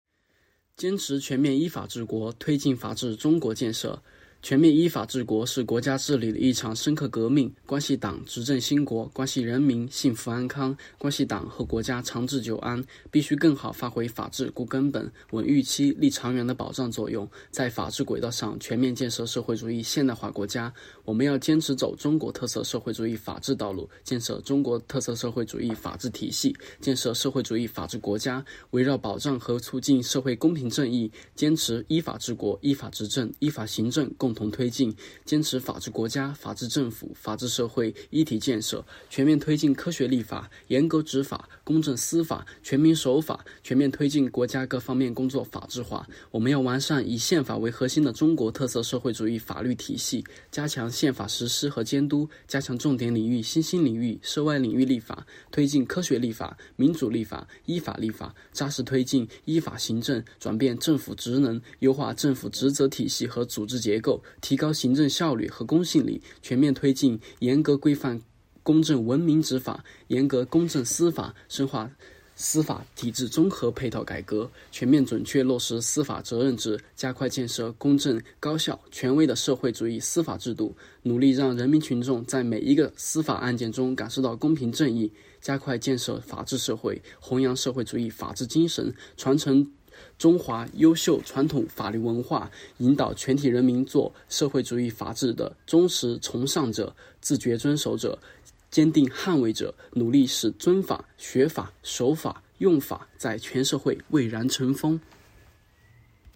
"喜迎二十大 经典咏流传"——西华大学马克思主义经典著作研读会接力诵读（003期）